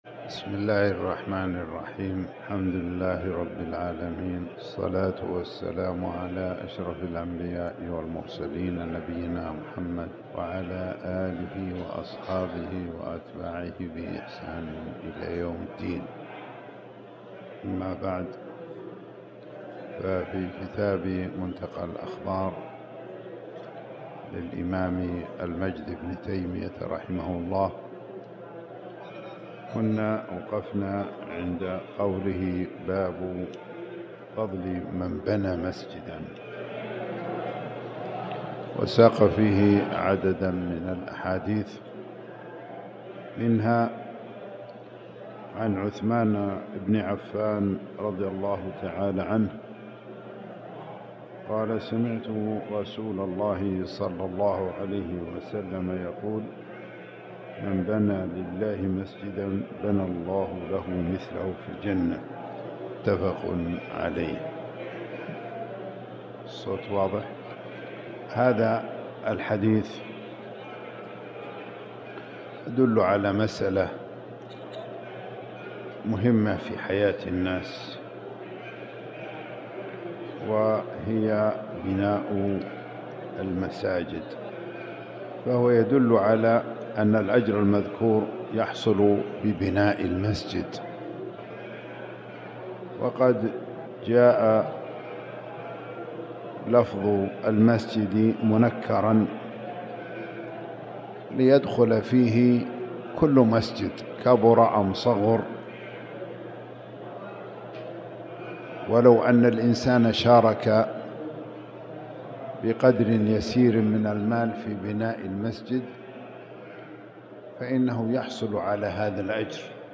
تاريخ النشر ١٢ رجب ١٤٤٠ هـ المكان: المسجد الحرام الشيخ